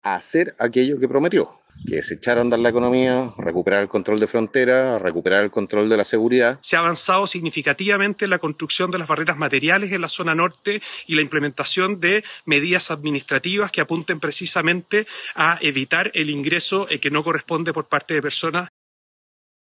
El presidente del Partido Nacional Libertario, Johannes Kaiser, delineó las prioridades hacia las que debería avanzar el gobierno, mientras que el timonel del Partido Republicano, Arturo Squella, valoró los avances en materia migratoria.